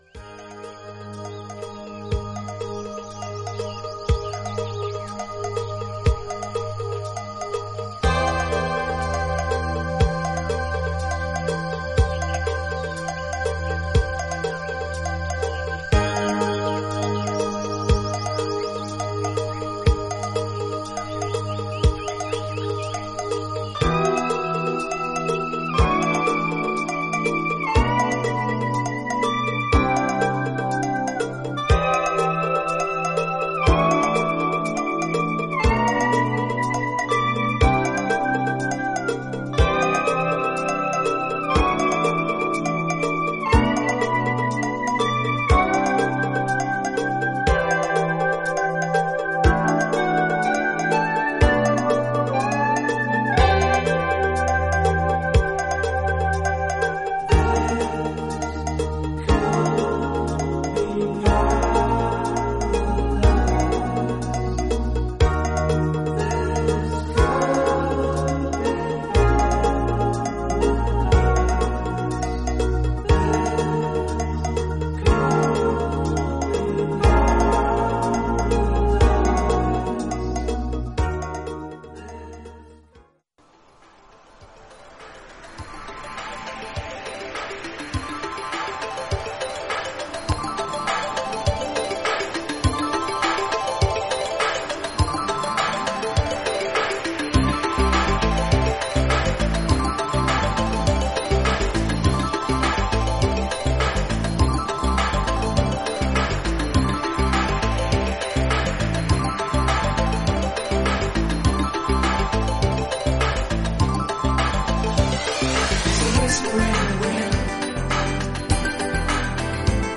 オリエンタルなメロディが印象的なバレアリック・ナンバー
トライバルなドラミングに様々な鍵盤の音色がレイヤーされていく